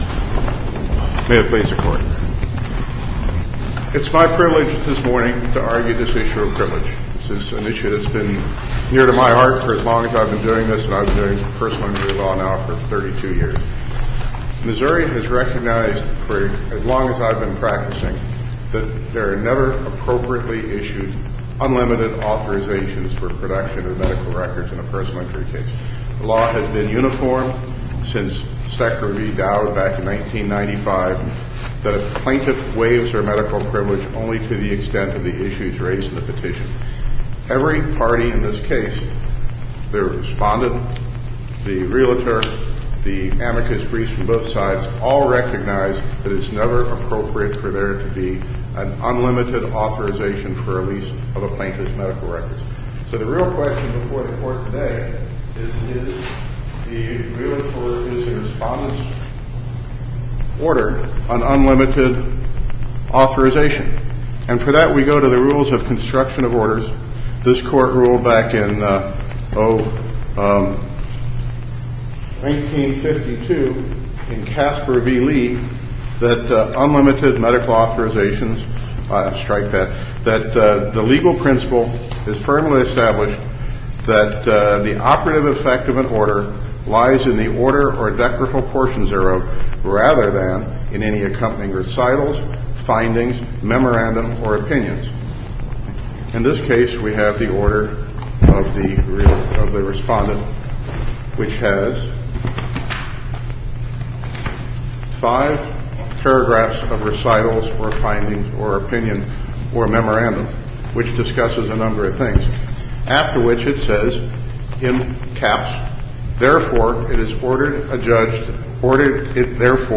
MP3 audio file of arguments in SC96103